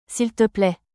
Also, be careful with pronunciation — though it looks like three words, it flows as a single unit: seel-tuh-play.